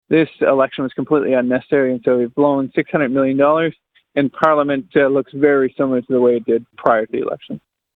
Lawrence spoke to Quinte News following his victory.